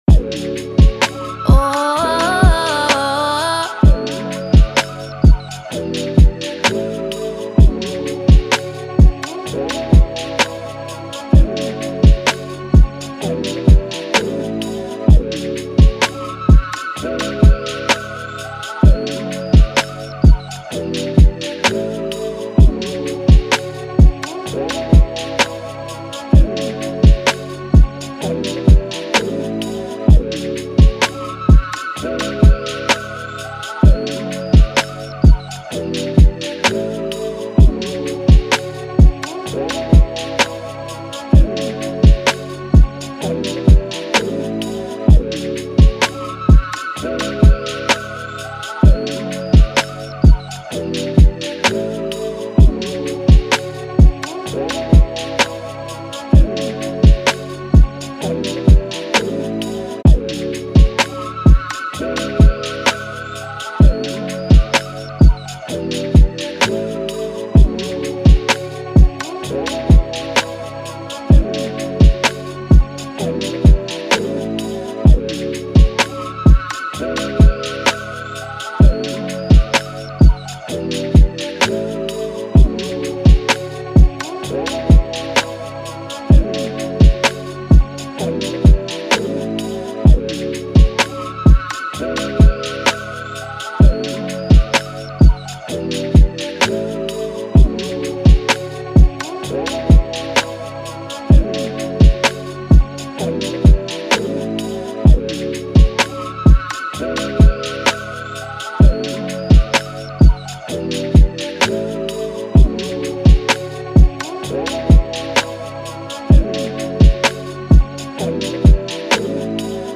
R&B, Trap
A Major